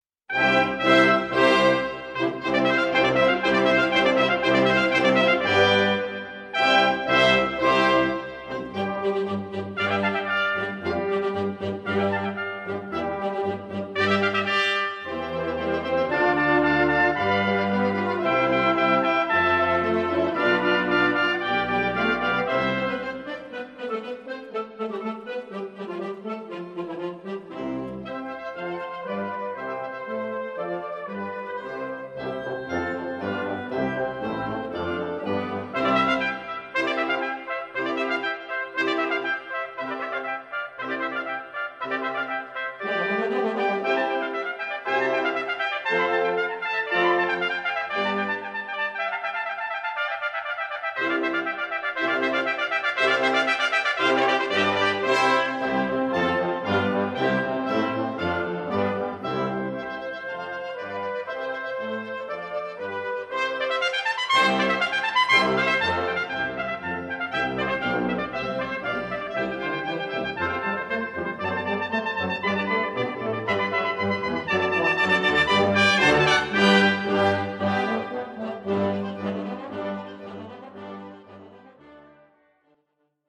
for 2 Bb or D Trumpets & Wind Band